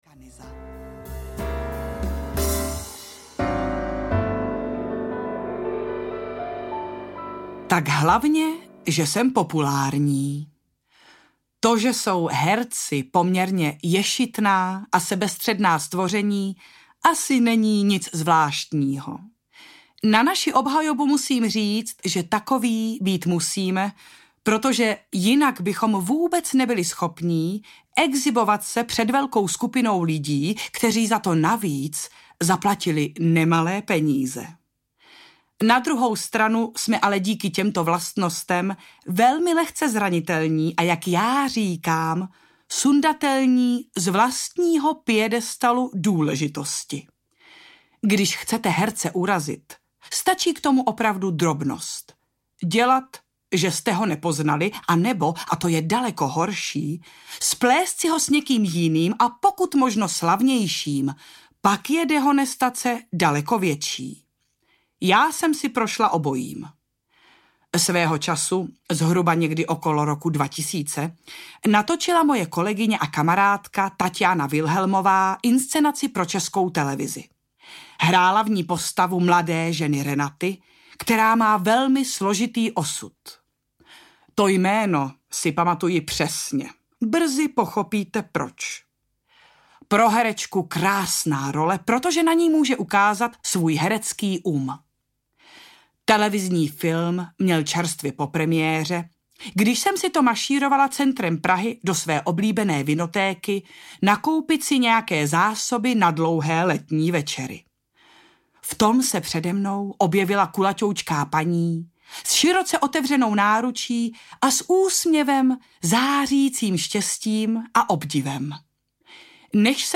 Buď v pogodě audiokniha
Ukázka z knihy